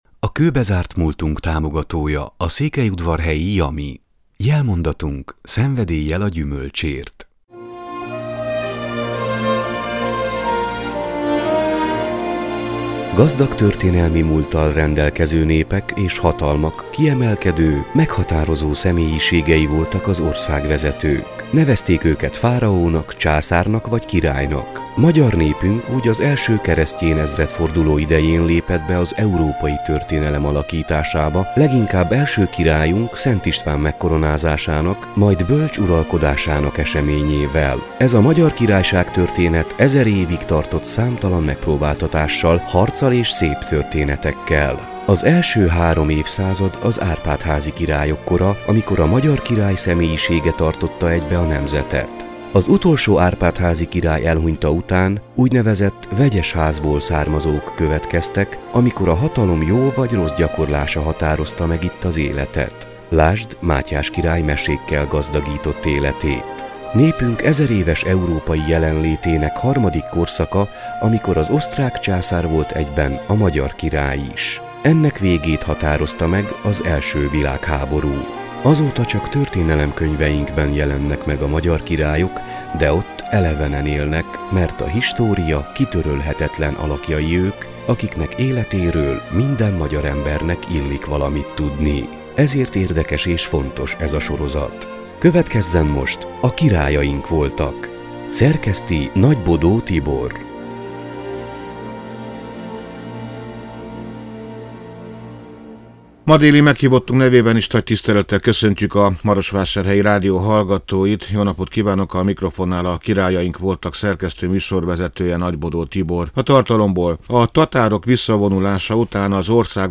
beszélget.